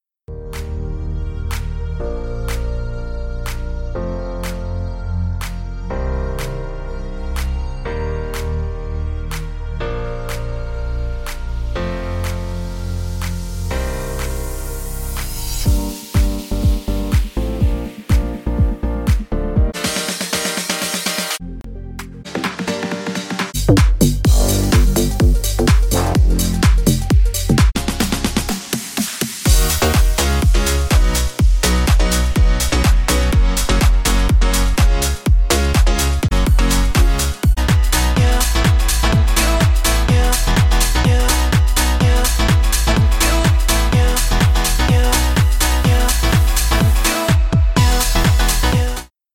这个包包括房子，进步的房子，拍打房子，舞蹈和流行元素。
• 123-126 BPM
• 25 个鼓循环（全/顶/踢和小军鼓）